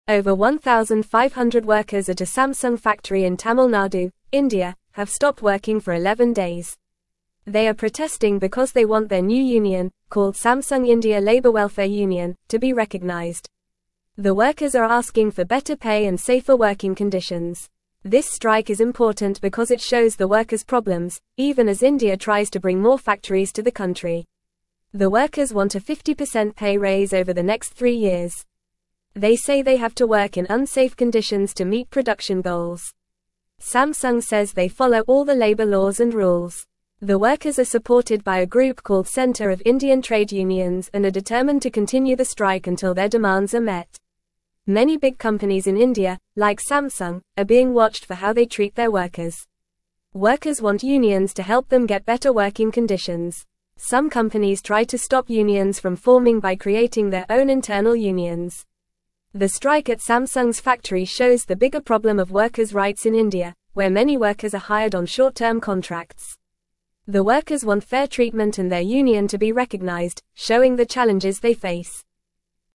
Fast
English-Newsroom-Lower-Intermediate-FAST-Reading-Workers-at-Samsung-in-India-stop-working-together.mp3